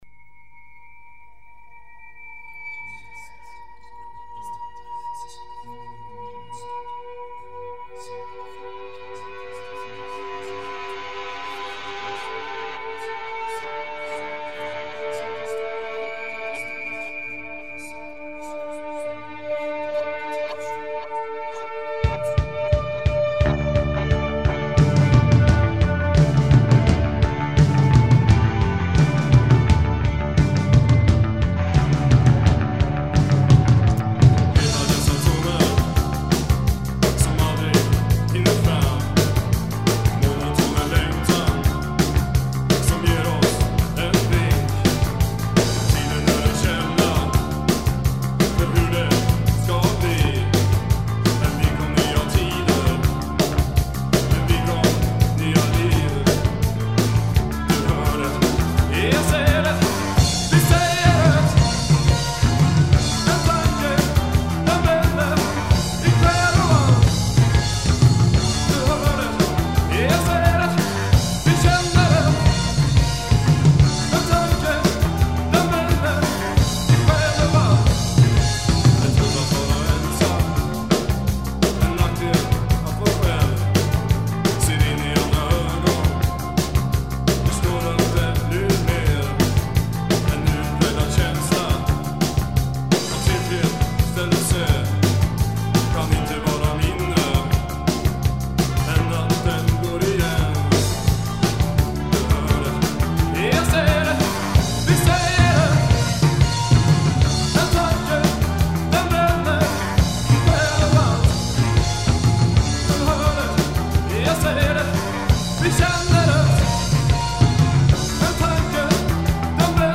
Bass
Guitar
Voice
Drums